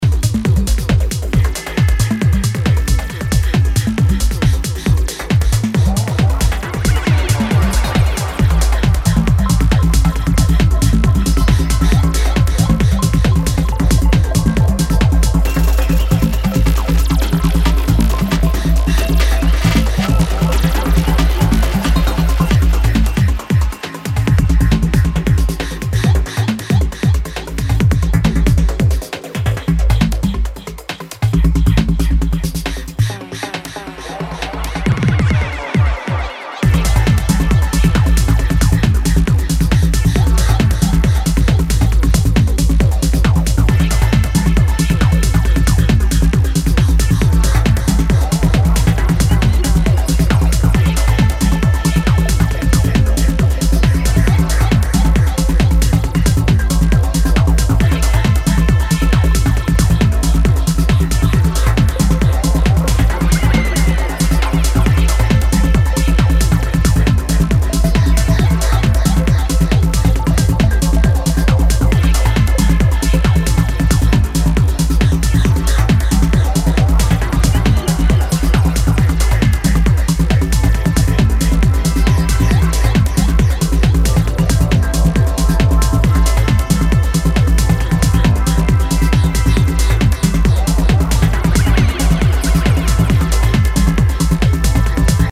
driving techno